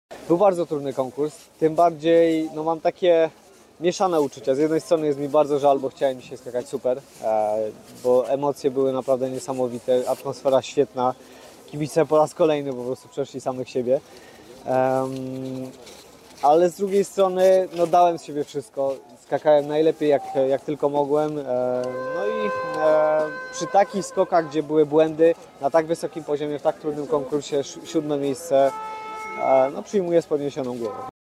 Kamil-Stoch-po-konkursie-indywidualnym.mp3